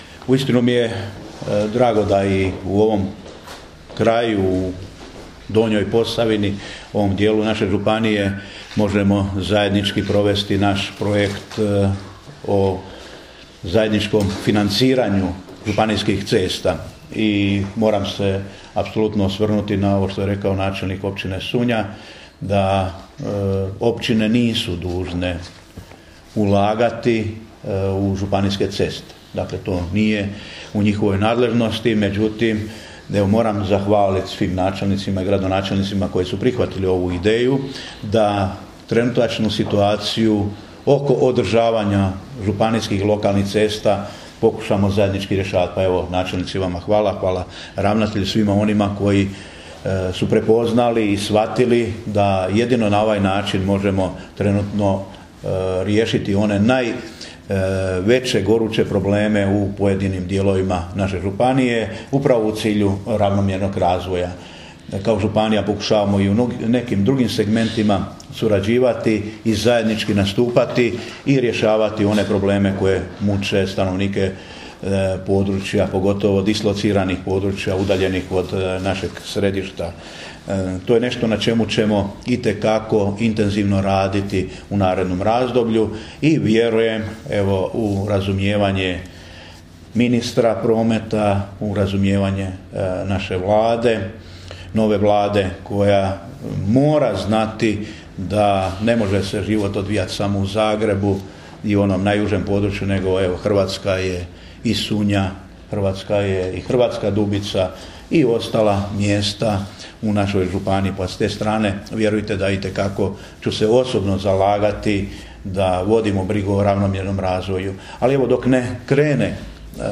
Izjava župana Ive Žinića: